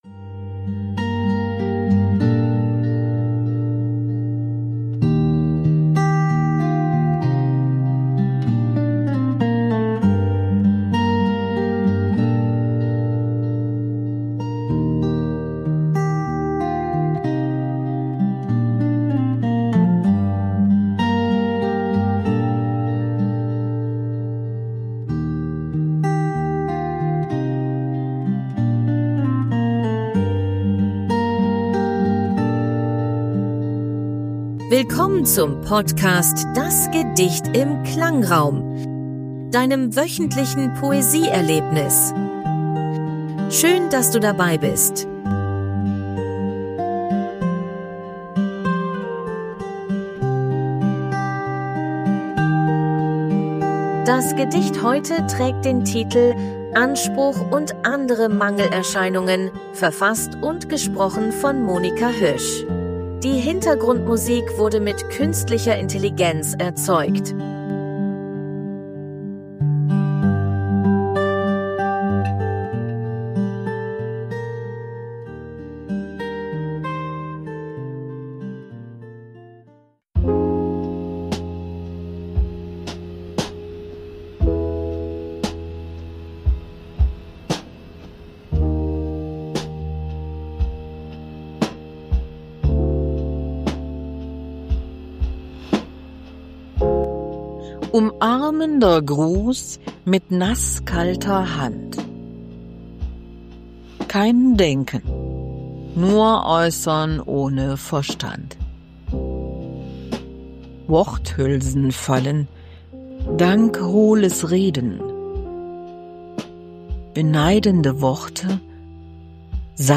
Freunde, die keine sind. Die Hintergrundmusik wurde mit KI erzeugt.